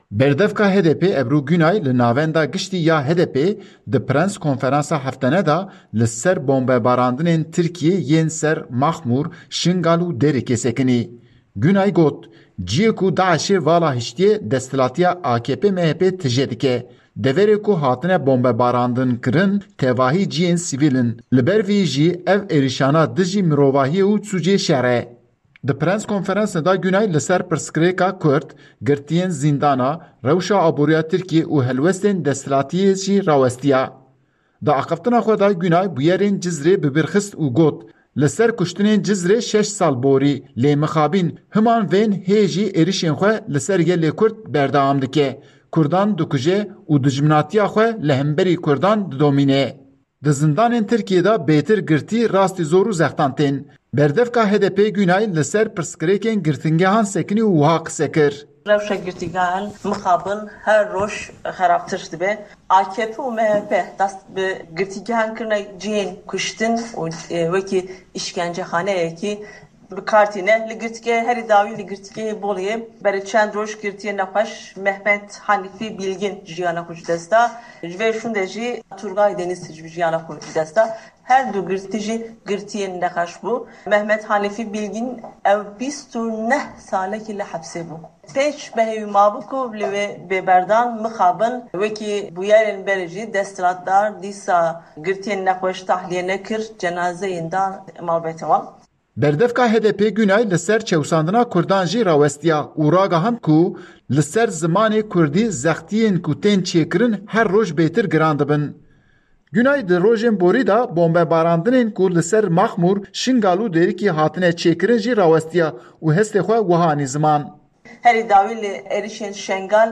Berdevka HDP’ê Ebru Gunay li Navenda Giştî ya HDP’ê di preskonferansa heftane de li ser bombebaranên Tirkiyê yên ser Maxmûr, Şengal û Dêrîkê sekinî.